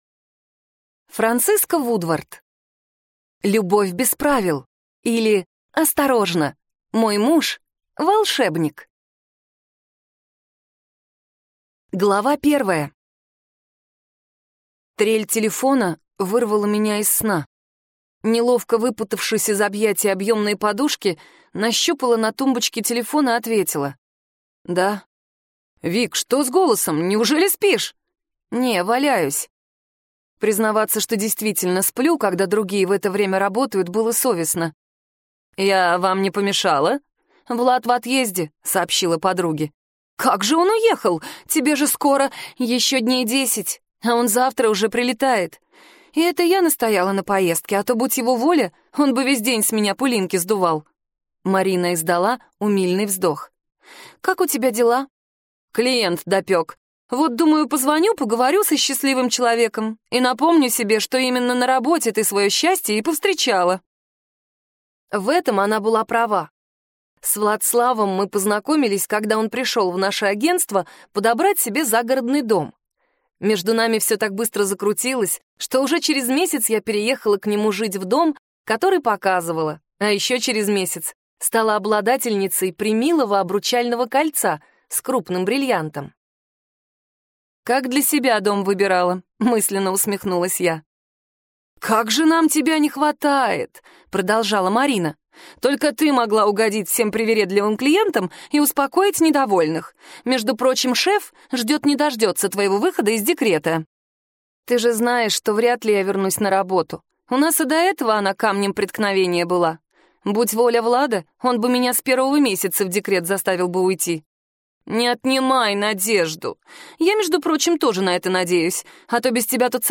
Аудиокнига Любовь без правил, или Осторожно! Мой муж – волшебник | Библиотека аудиокниг